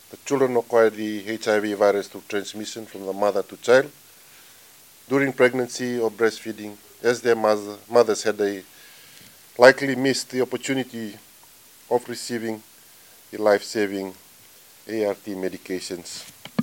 Health Minister, Atonio Lalabalavu.